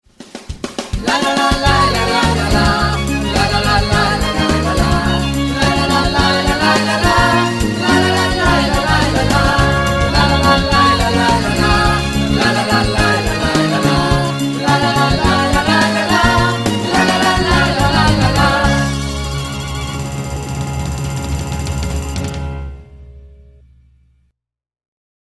Microfono registrazione voci AKG C 414 XLII